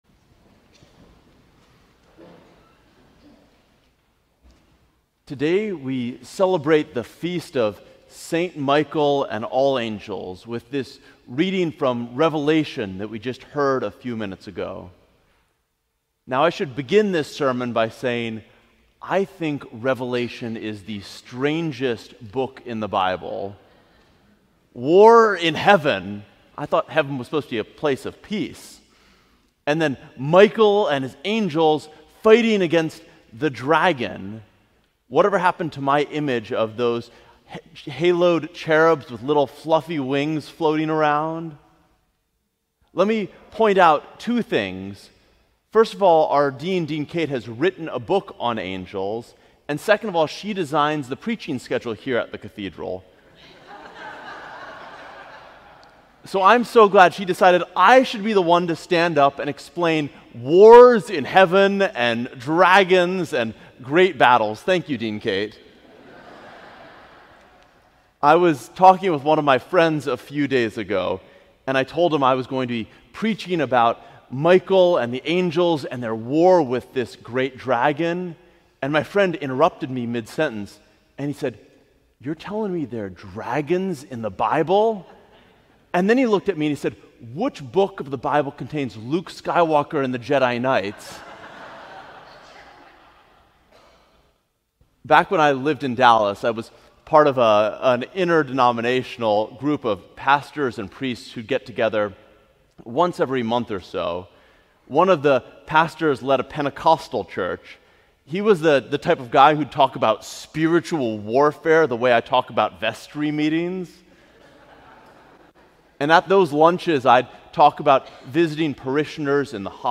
Sermon: Checkmate